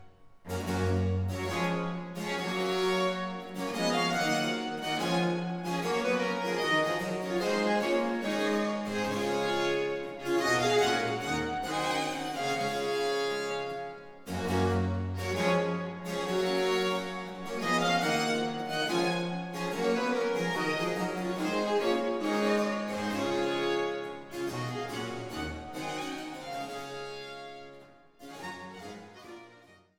Violoncello
Theorbe
Cembalo, Polygonalspinet